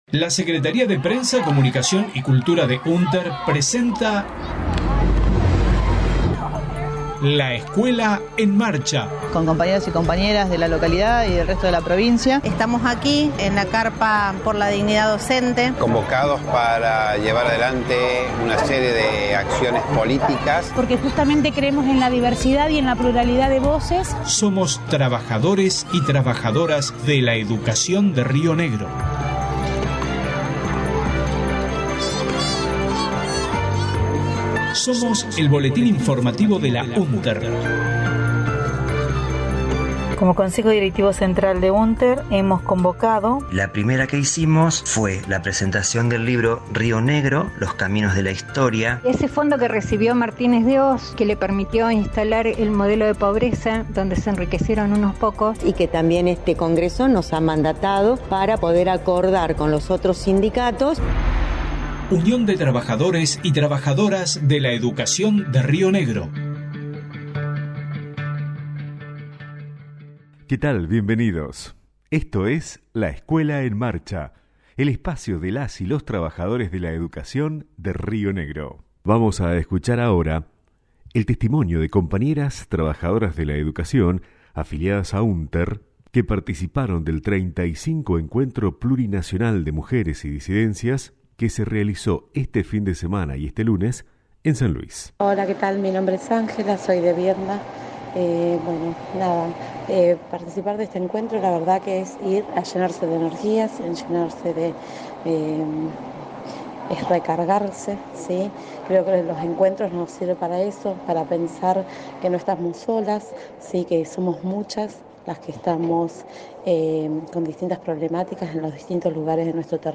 LEEM 11/10/22, Voces de participantes en el 35° Encuentro Plurinacional de Mujeres Lesbianas, Travestis, Trans, Bisexuales, Intersexuales y No Binaries, realizado en San Luis desde el 8 al 10 de octubre, participaron 131 trabajadorxs de la educación de UnTER de los más de cien talleres, debates, actividades culturales propuestas y de la marcha con la fuerza que convoca. El encuentro 36º será en Bariloche por los femicidios en Río Negro, para visibilizar y parar la represión al pueblo mapuche.